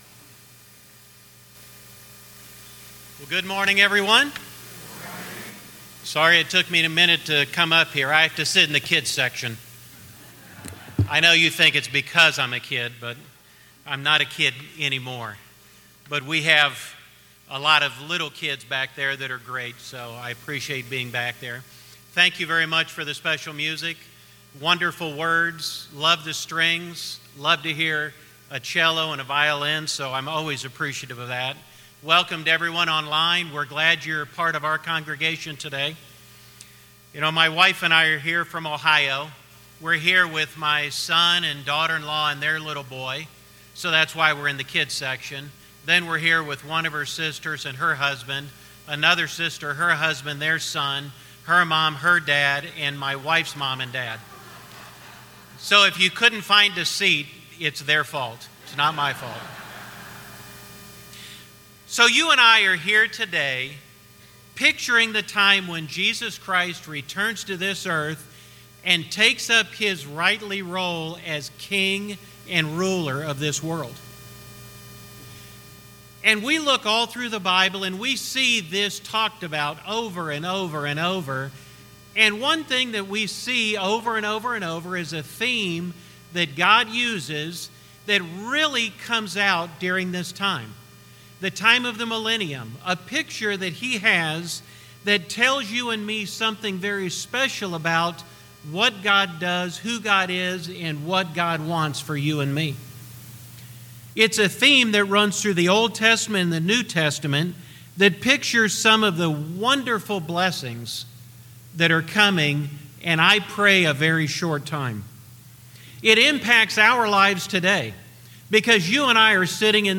Given in Temecula, California